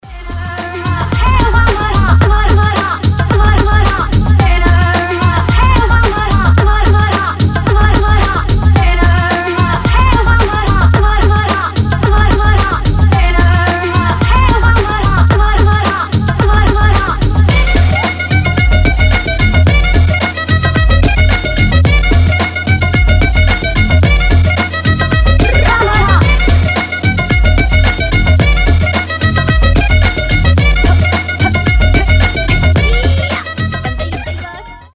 • Качество: 320, Stereo
восточные
быстрые
необычные
breakbeat